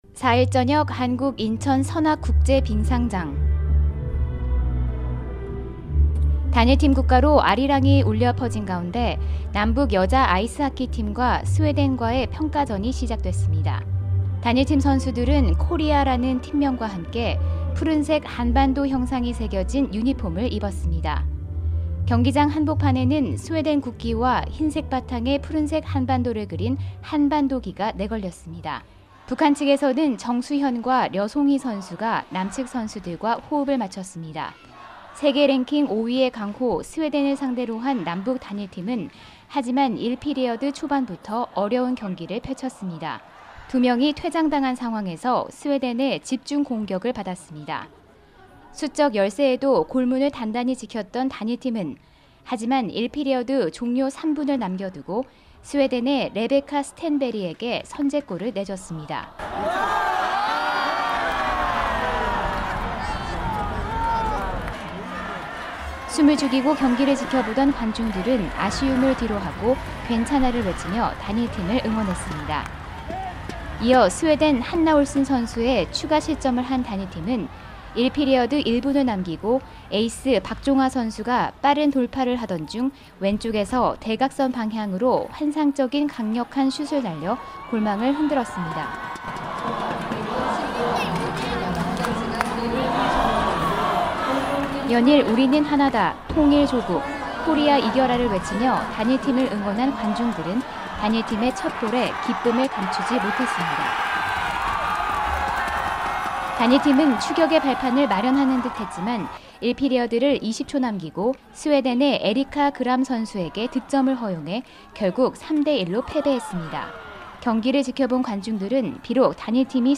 [특파원 리포트 오디오] 남북 단일팀 첫 평가전, 스웨덴에 3:1 패배…관중 “올림픽 선전 기대”